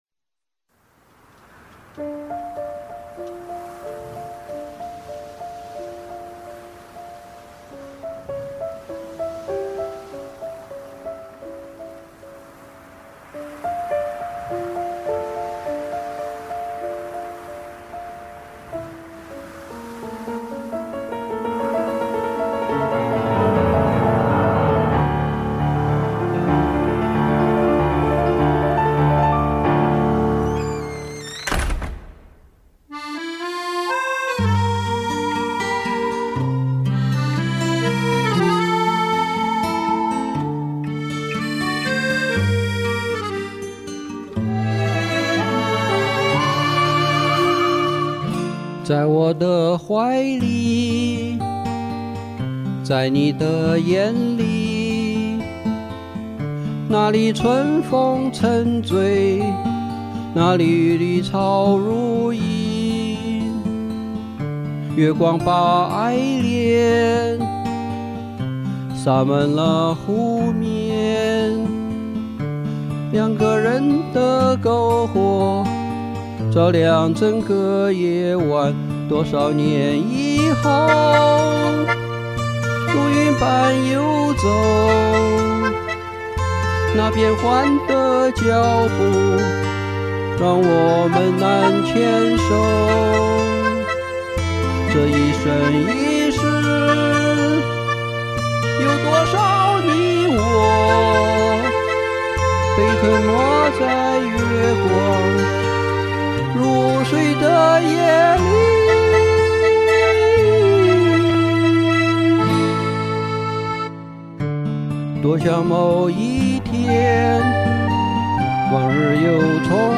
深情细腻纯净好唱！假音好听！
音色纯净，演唱到位，那个高音也唱得好，赞